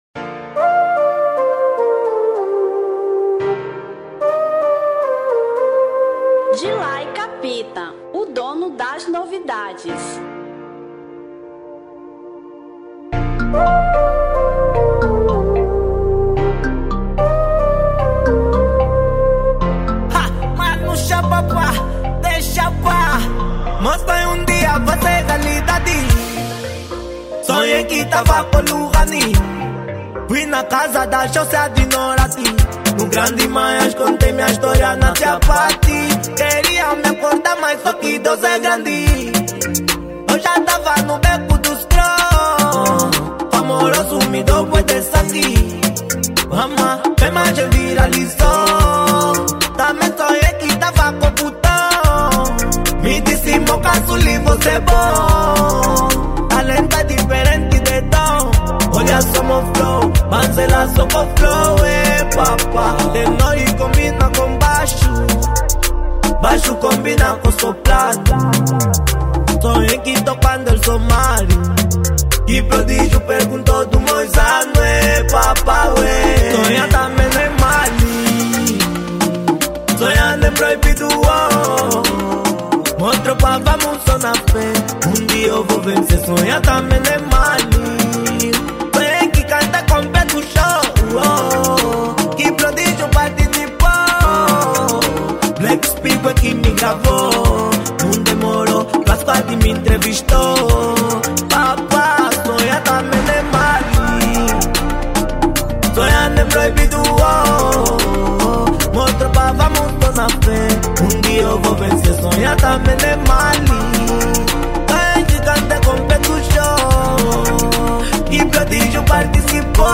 Kuduro 2024